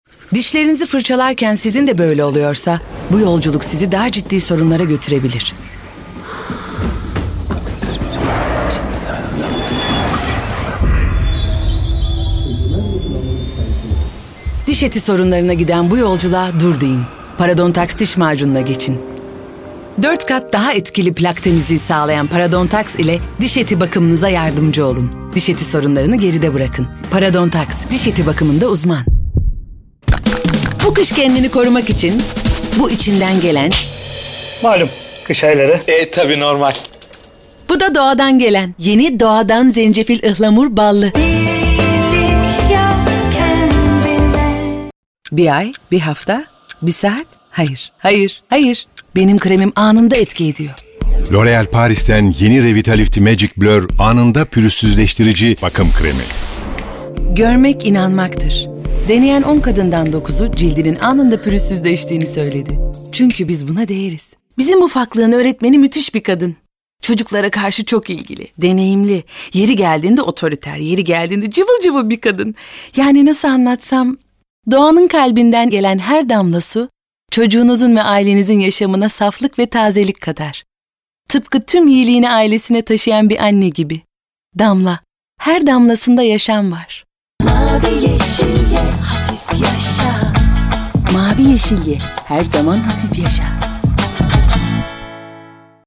Kadın
IVR Canlı, Eğlenceli, Güvenilir, Karakter, Seksi, Karizmatik, Vokal, Promosyon, Sıcakkanlı, Tok / Kalın, Dış Ses, Dostane,